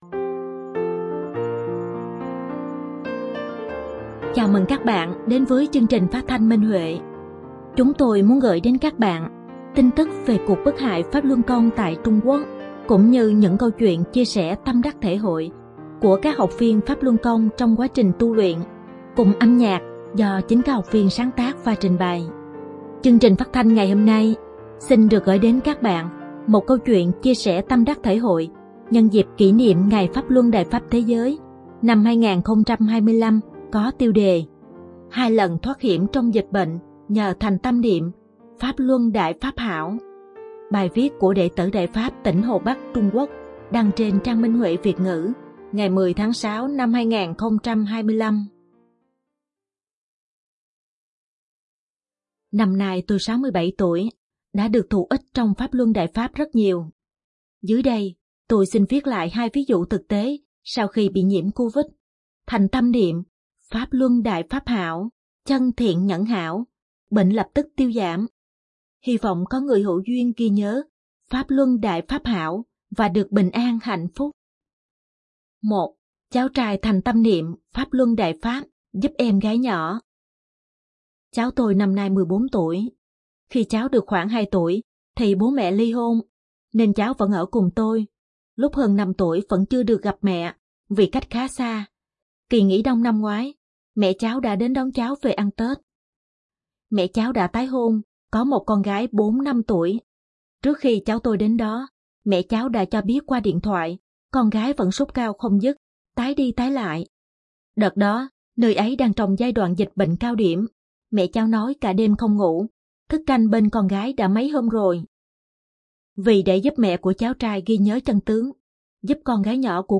Chúng tôi muốn gửi đến các bạn thông tin về cuộc bức hại Pháp Luân Công tại Trung Quốc cũng như những câu chuyện chia sẻ tâm đắc thể hội của các học viên trong quá trình tu luyện, cùng âm nhạc do chính các học viên sáng tác và trình bày.
Chương trình phát thanh số 22: Bài viết chia sẻ tâm đắc thể hội nhân dịp Chúc mừng Ngày Pháp Luân Đại Pháp Thế giới 2025 trên Minh Huệ Net có tiêu đề Hai lần thoát hiểm trong dịch bệnh nhờ thành tâm niệm “Pháp Luân Đại Pháp hảo”, bài viết của đệ tử Đại Pháp tại Đại Lục.